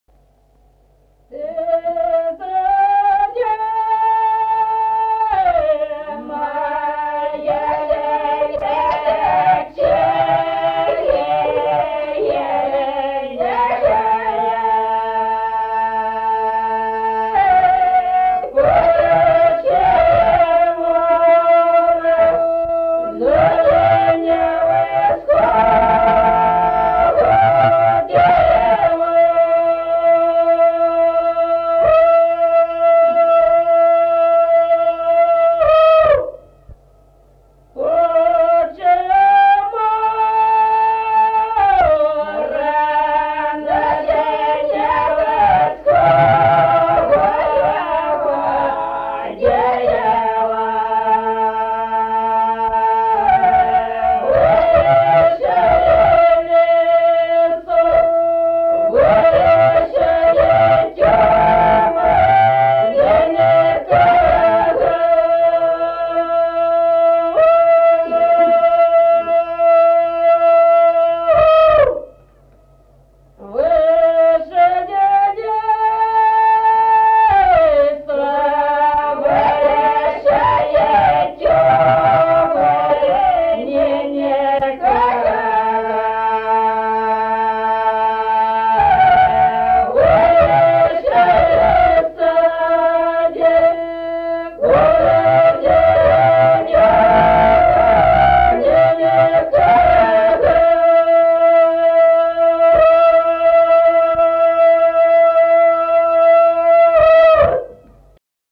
Песни села Остроглядово.